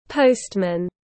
Bưu tá tiếng anh gọi là postman, phiên âm tiếng anh đọc là /ˈpəʊst.mən/.
Postman /ˈpəʊst.mən/